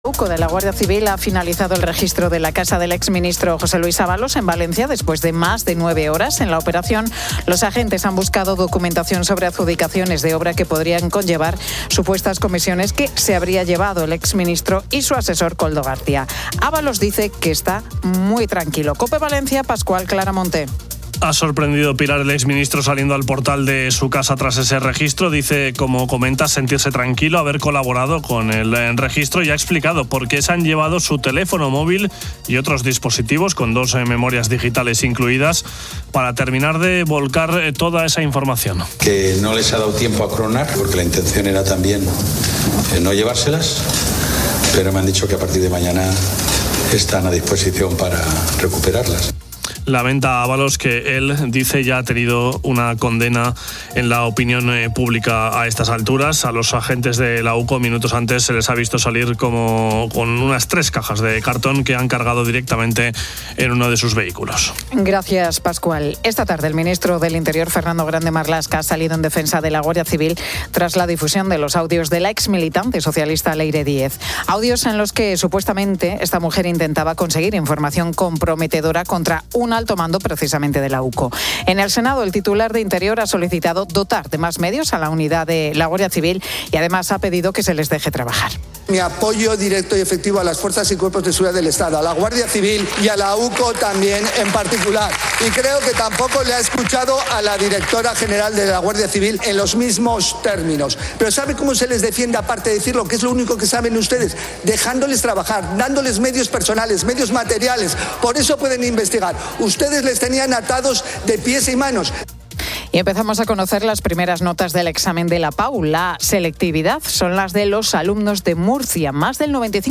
La Tarde 18:00H | 10 JUN 2025 | La Tarde Pilar García Muñiz conoce cómo palabras como "fontanera" o "chiringuito" se han reconvertido negativamente. También entrevista a India Martínez, que presenta su nuevo disco "Aguachile".